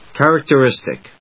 音節char・ac・ter・is・tic 発音記号・読み方
/k`ærəktərístɪk(米国英語), ˌkerɪktɜ:ˈɪstɪk(英国英語)/